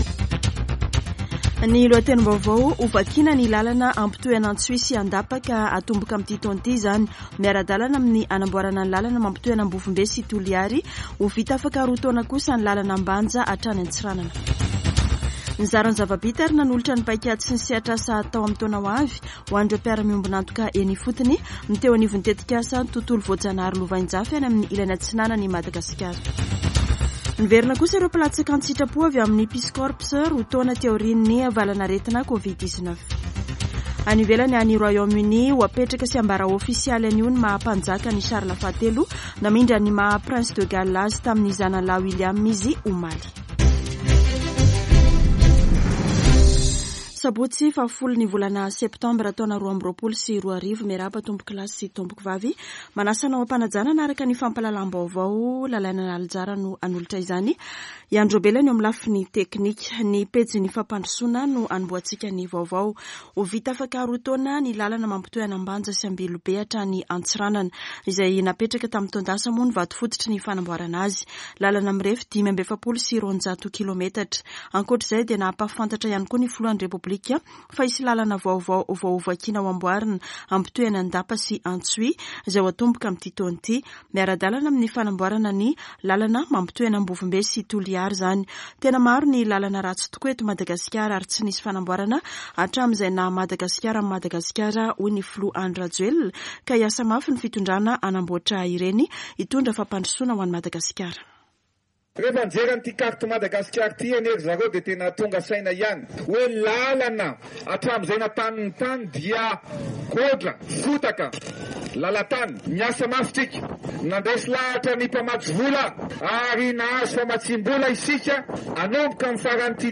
[Vaovao maraina] Sabotsy 10 septambra 2022